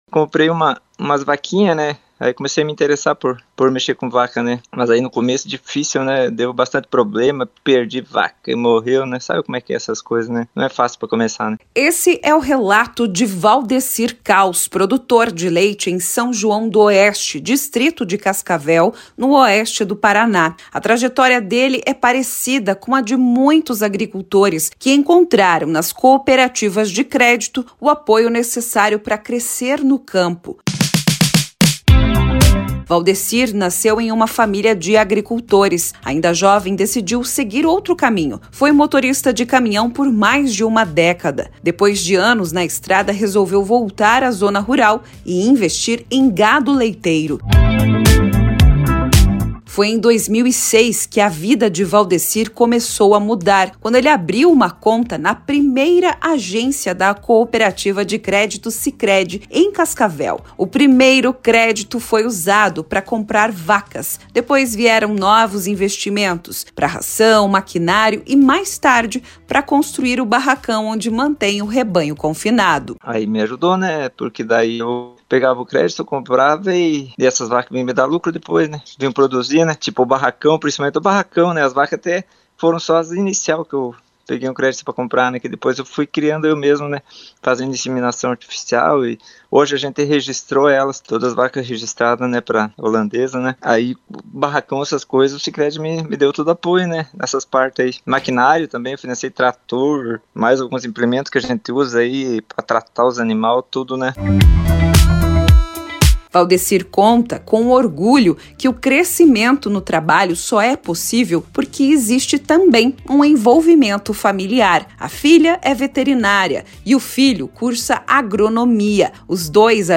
Reportagem-1-O-credito-que-volta-para-a-terra.mp3